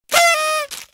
party-horn-sound-effect-free-download.mp3